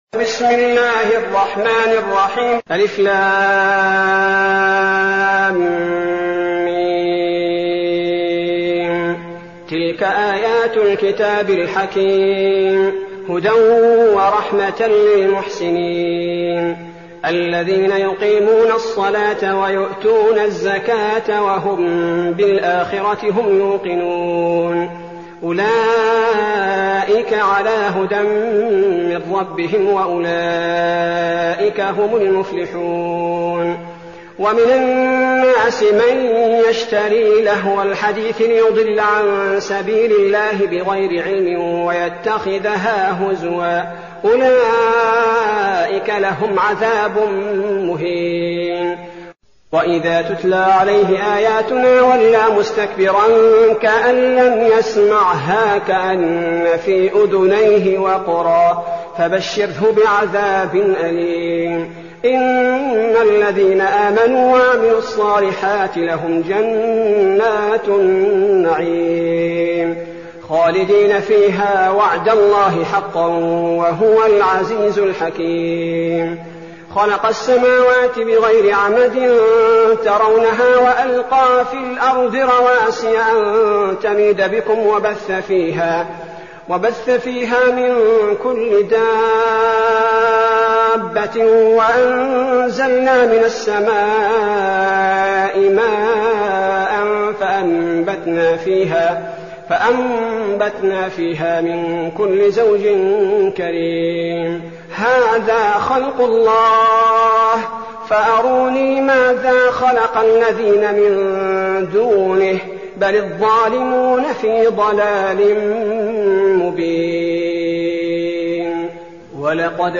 المكان: المسجد النبوي الشيخ: فضيلة الشيخ عبدالباري الثبيتي فضيلة الشيخ عبدالباري الثبيتي لقمان